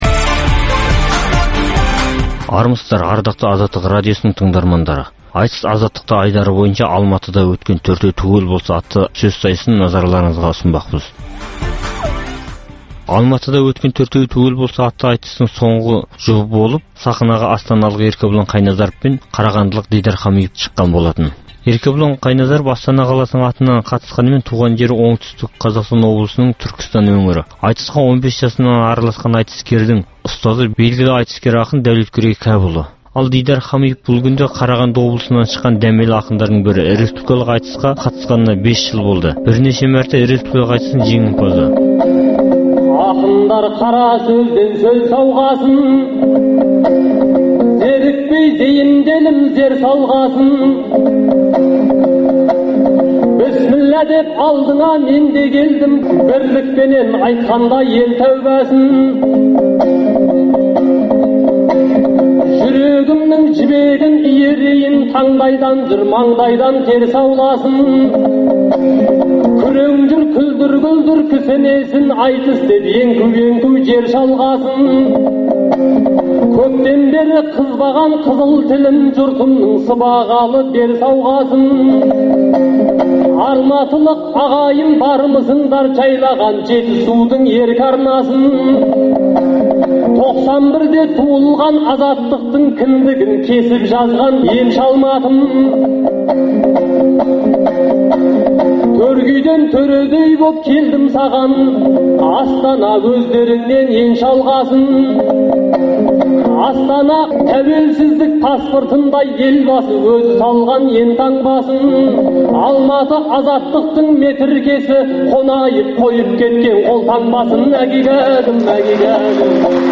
Айтыс - Азаттықта
бүгін сіздердің назарларыңызға "Төртеу түгел болса!" деген атпен Алматыда өткен айтыста сөз сайыстырған соңғы жұп